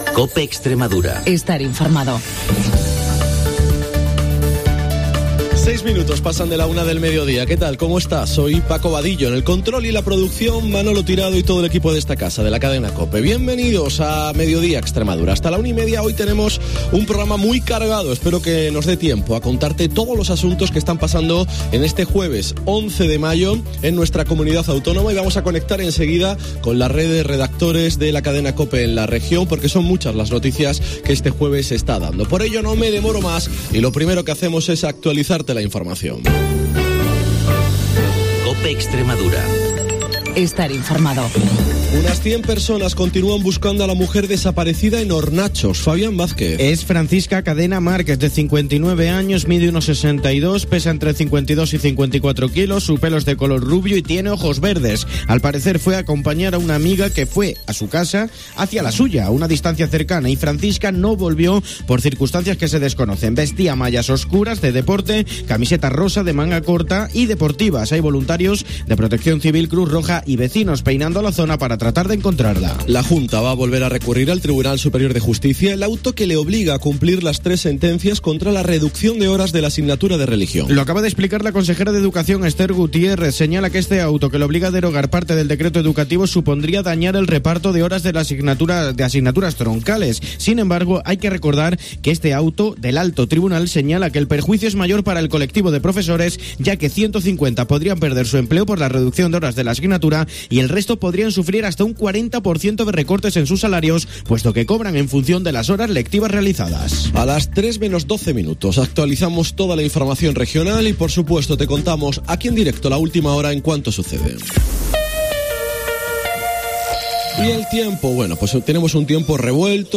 En el programa de hoy hemos hablado del Mes del Emprendimiento y también, entre otros asuntos, hemos entrevistado al cantante Pau Donés con motivo de su concierto el 26 de mayo en el López de Ayala de Badajoz.